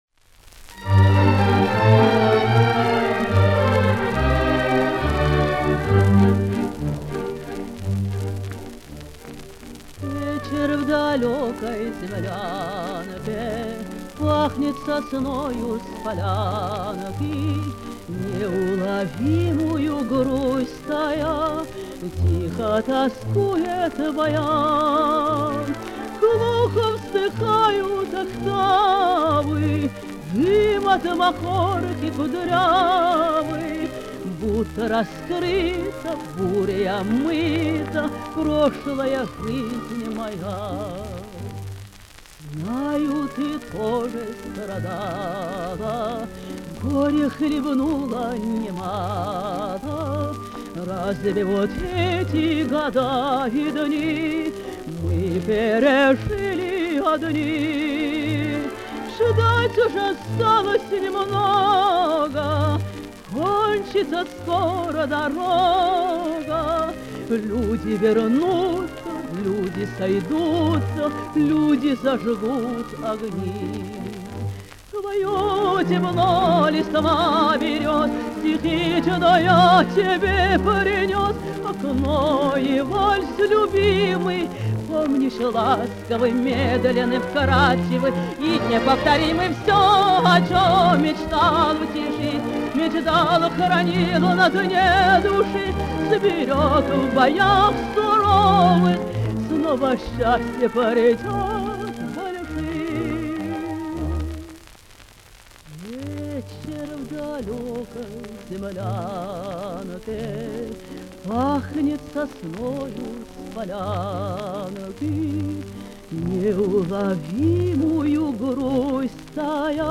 Каталожная категория: Контральто с оркестром |
Жанр: Песня
Вид аккомпанемента:    Оркестр |
Место записи:    Москва |